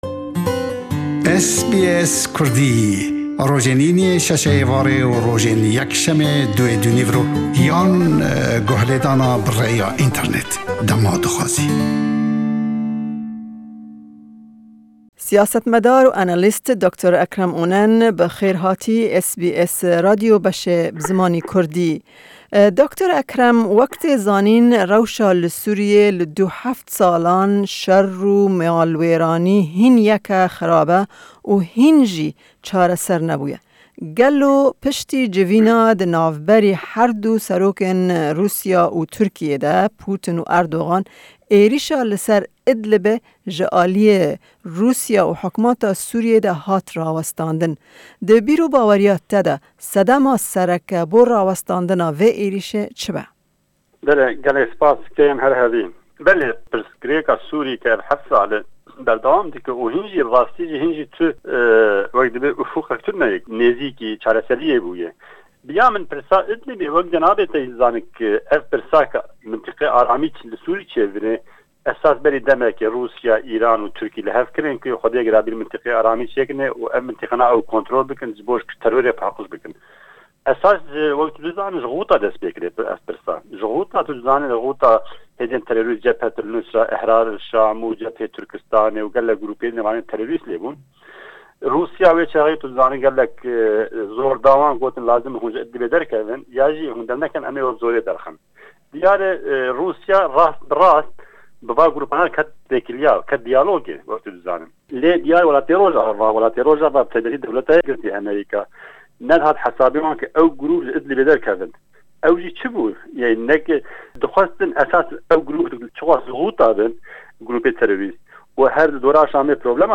Di hevpeyvînê de herweha behs li ser peymana Rûsya û Tirkiyê ya ku li Sochi derbarê Idlibê cî girtî jî dibe.